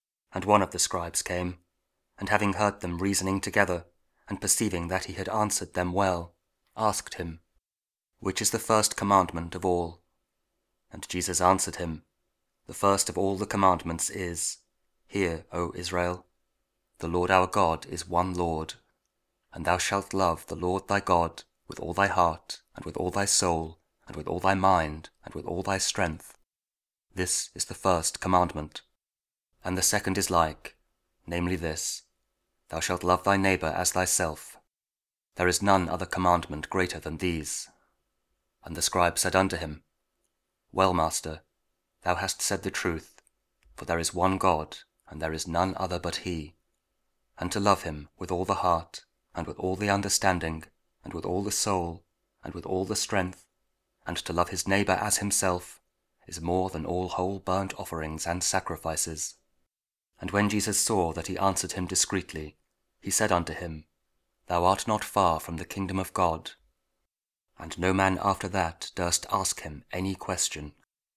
Mark 12: 28-34 – 31st Sunday Year B, also Lent Week 3, Friday (Audio Bible KJV, Spoken Word)